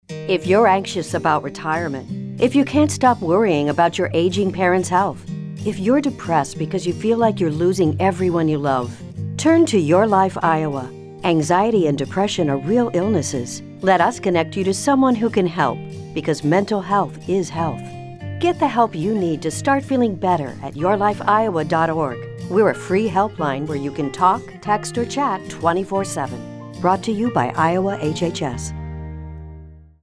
:30 Radio | Let Us Help You | (Female Voice 2)
Radio spot :30 Radio | Let Us Help You | (Female Voice 2) This campaign promotes older adults to reach out for help when experiencing anxiety, depression, and other mental health symptoms.
YLI Adult Mental Health Radio Spot Female 2_0.mp3